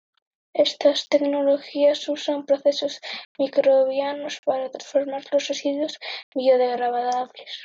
trans‧for‧mar
/tɾansfoɾˈmaɾ/